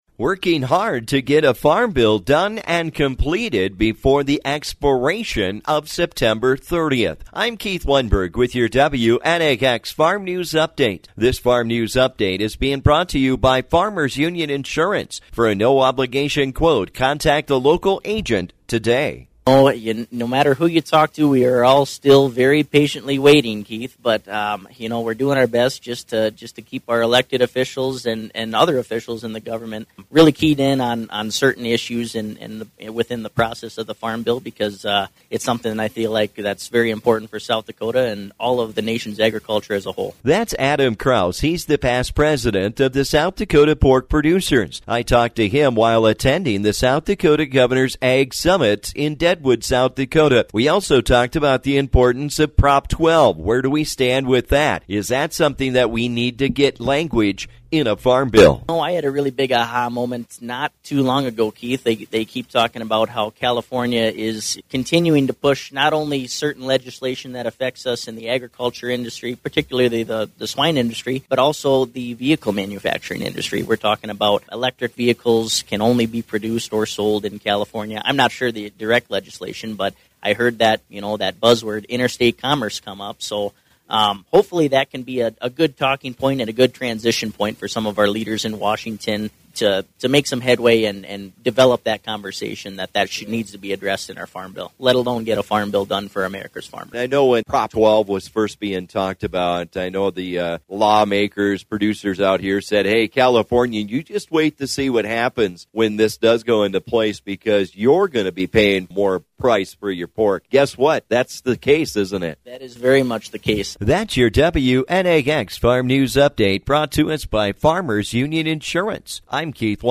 Today I talk with the South Dakota Pork Producers about the Farm Bill and Prop 12.